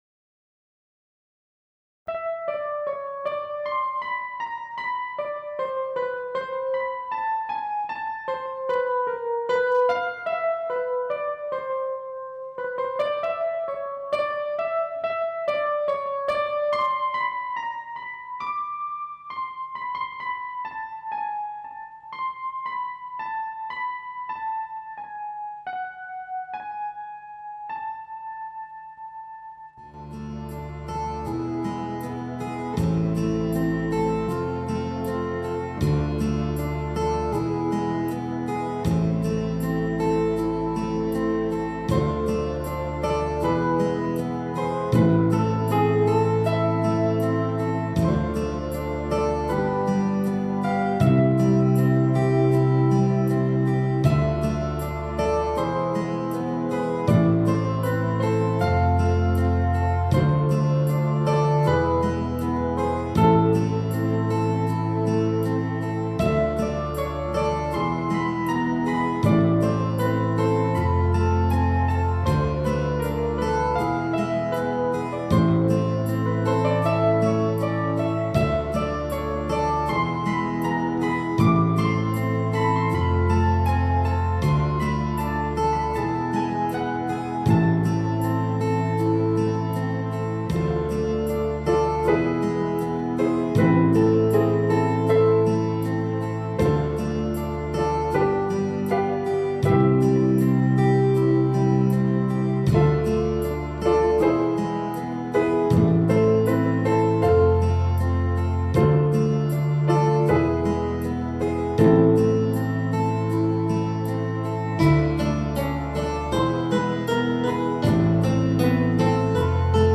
Мелодия Инструменталка Инструментальная